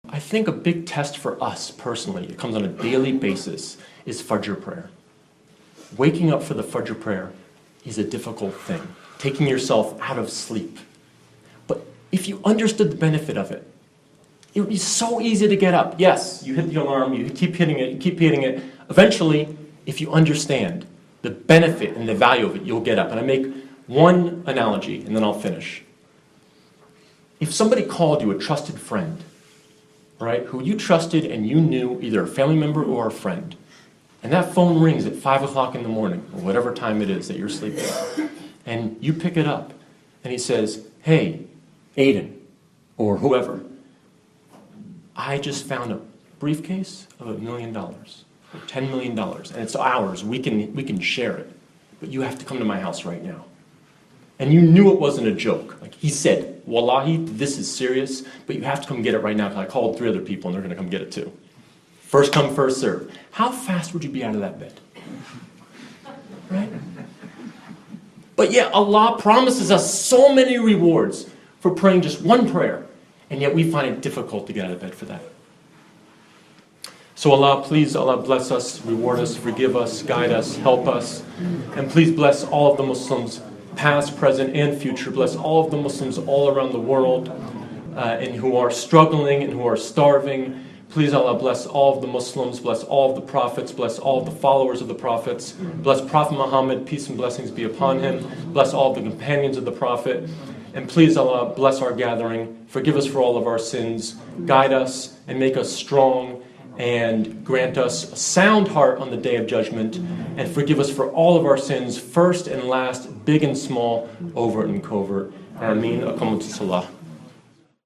A Quick Pep Talk For Praying Fajr On Time